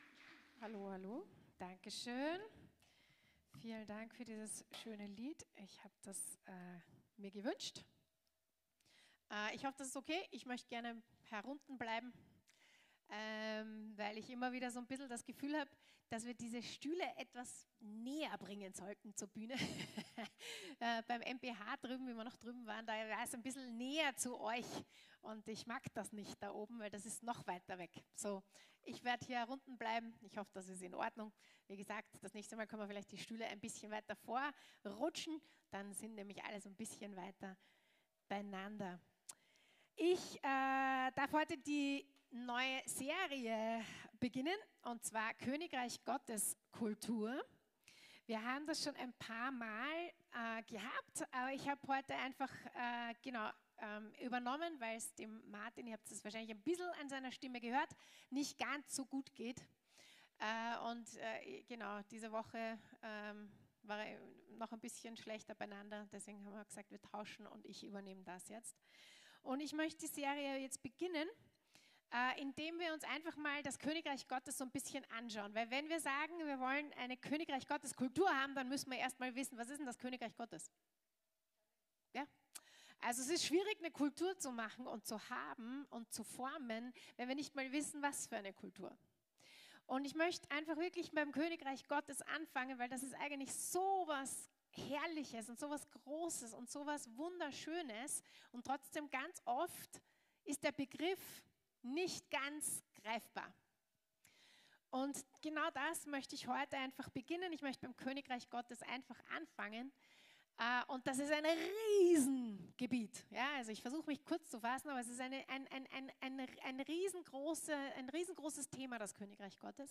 Lobpreisgottesdienst